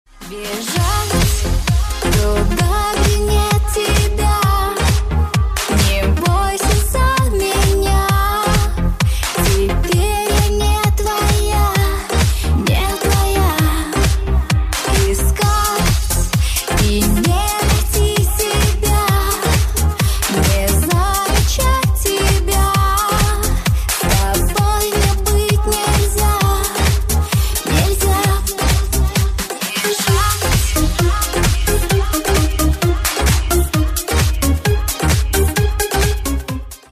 женский вокал
dance
электронная музыка
спокойные
попса
красивый женский голос
русская попса
поп-музыка